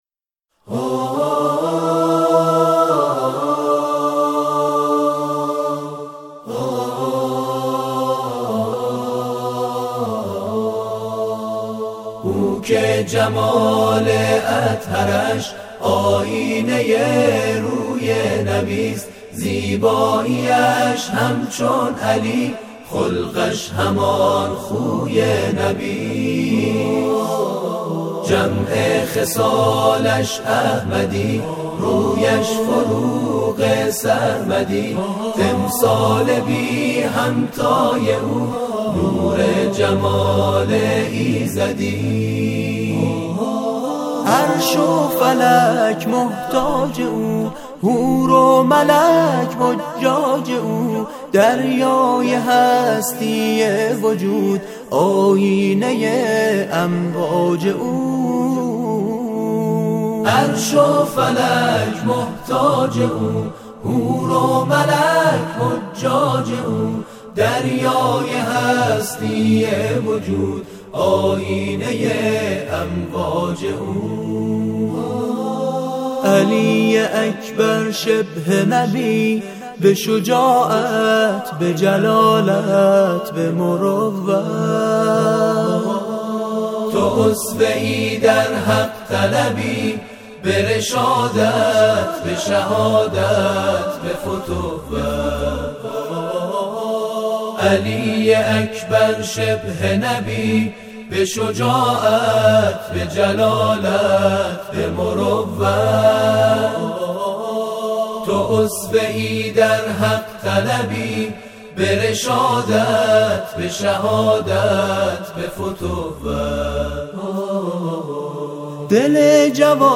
مدیحه‌سرایی به مناسبت میلاد حضرت علی‌اکبر(ع)
گروه شبکه اجتماعی: گروه تواشیح سیرت‌النبی(ص) مشهد مقدس به مناسبت میلاد باسعادت حضرت علی‌اکبر(ع) به اجرای برنامه پرداختند.
به گزارش خبرگزاری بین‌المللی قرآن(ایکنا) گروه تواشیح و هم‌سرایی سیرت‌النبی(ص) مشهد مقدس به مناسبت میلاد با‌سعادت حضرت علی‌اکبر(ع)، مدیحه‌سرایی به این مناسبت در گروه تلگرامی خود منتشر کرده است که در ادامه ارائه می‌شود.